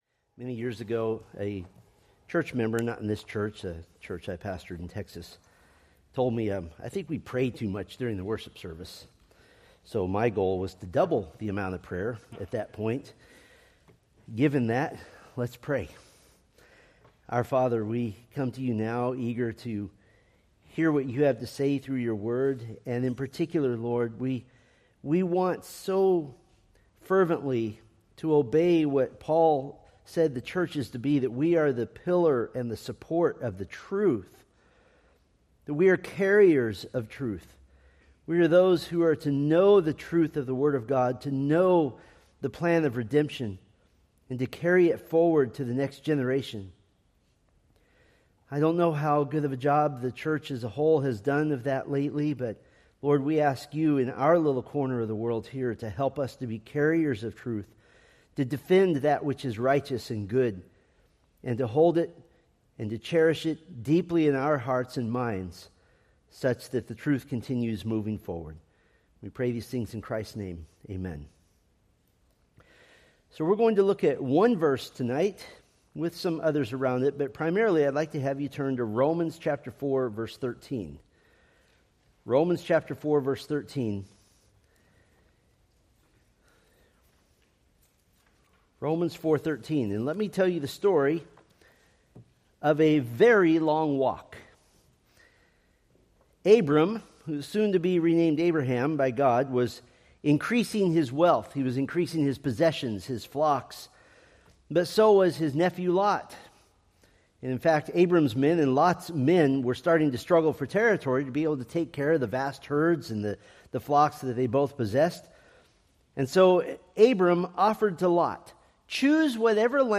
Preached February 8, 2026 from Selected Scriptures